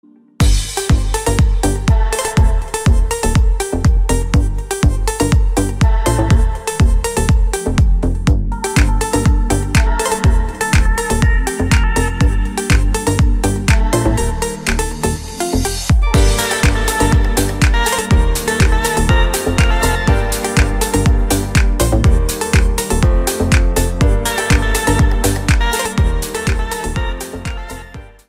• Качество: 192, Stereo
deep house
восточные мотивы
Electronic
красивая мелодия
Клубный рингтон в стиле deep house.